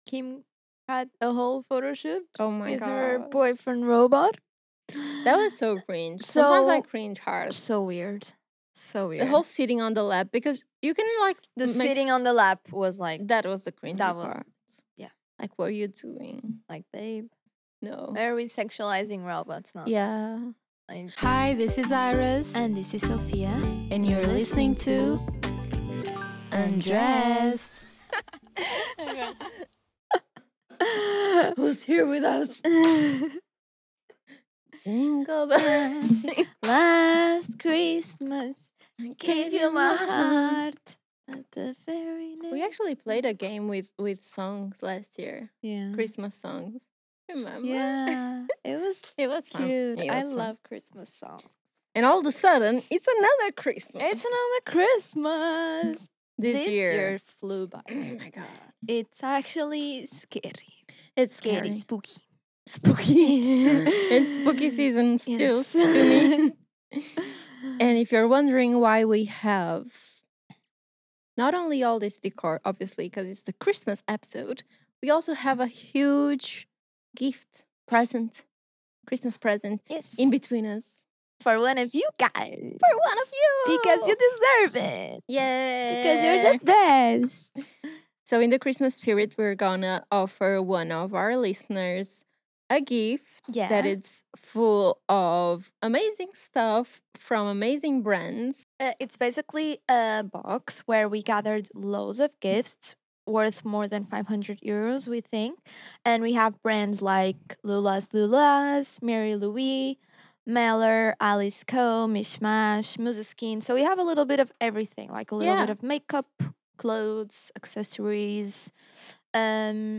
Just a casual coffee conversation between two besties that we hope you enjoy and have fun listening to!